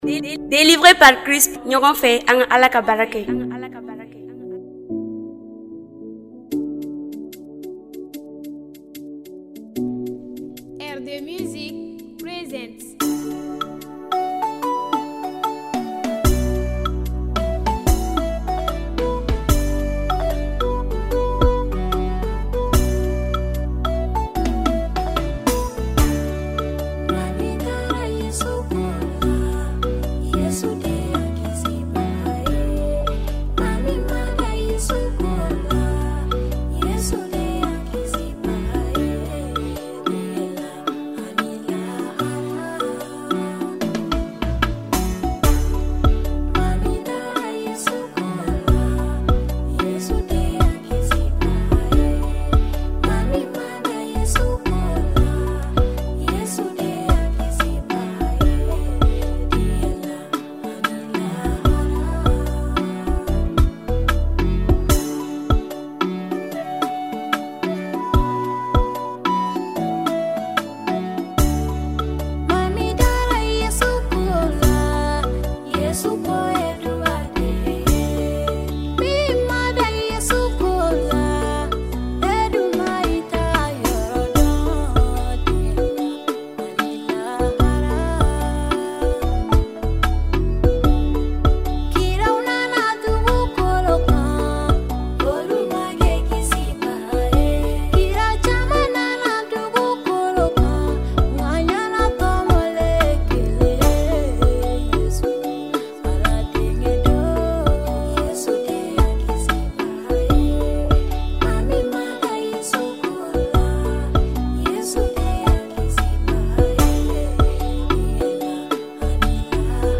La chantre